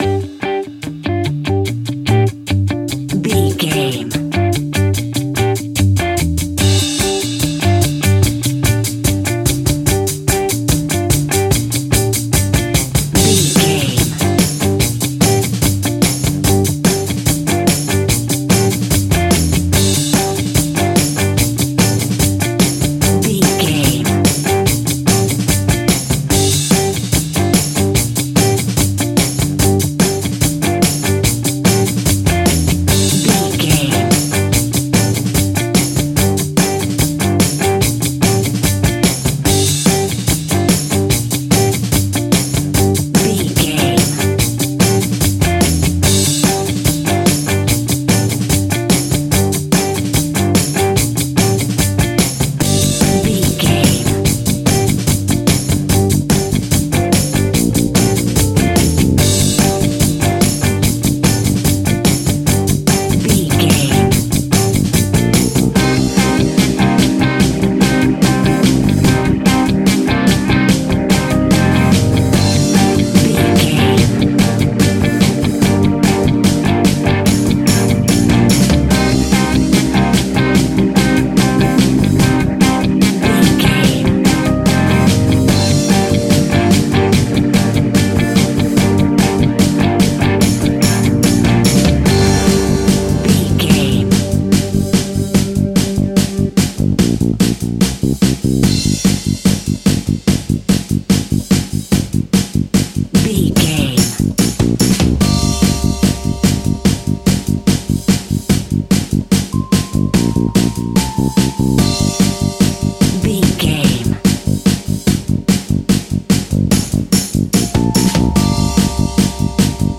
Aeolian/Minor
cool
uplifting
bass guitar
electric guitar
drums
60s
cheerful/happy